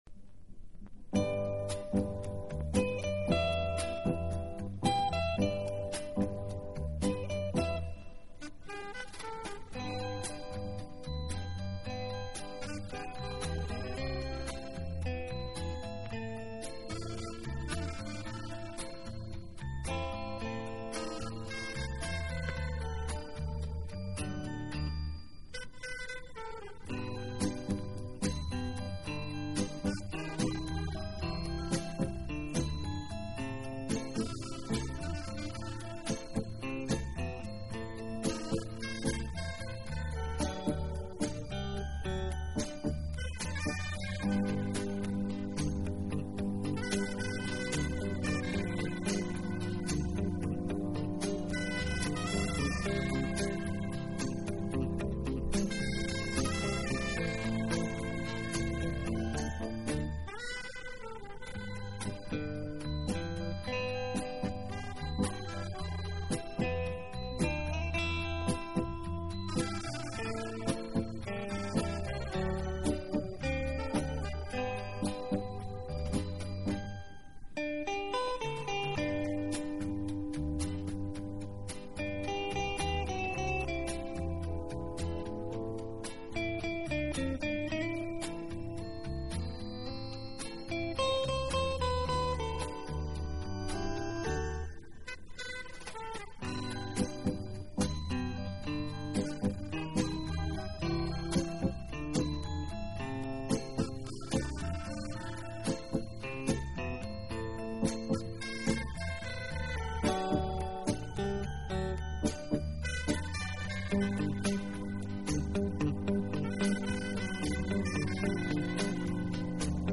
用萨克管演奏情调爵士乐，上世纪六十年代开始很走红，到上个世纪七十年